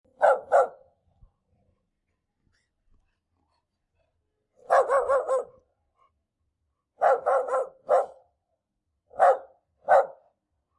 Dog Barking Sound